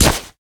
damage_wolf3.ogg